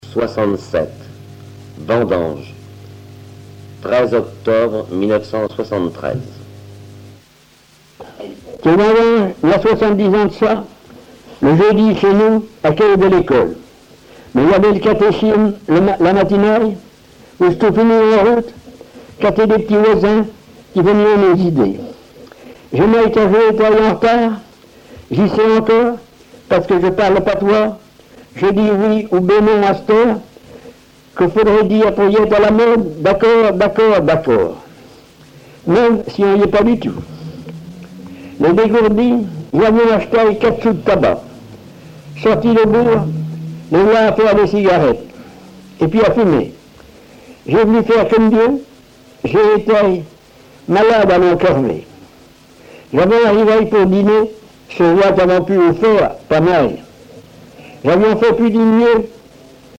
Récits en patois
Catégorie Récit